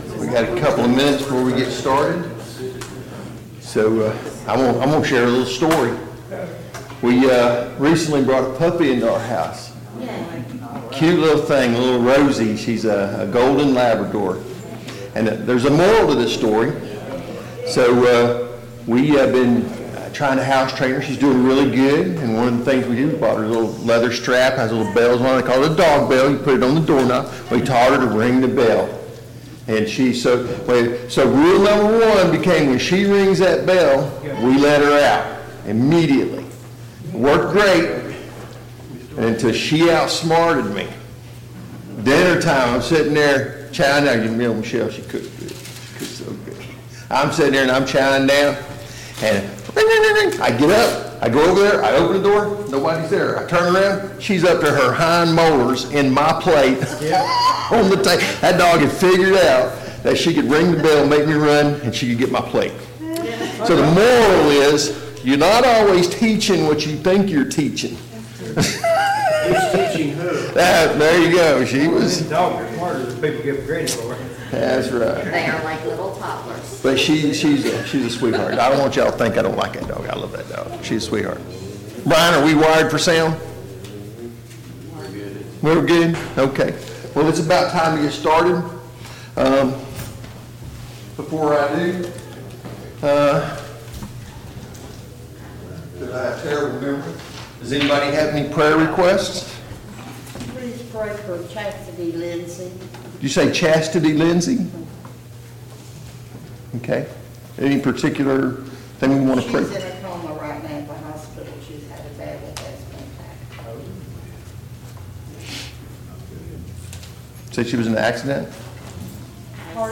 Sunday Morning Bible Class Topics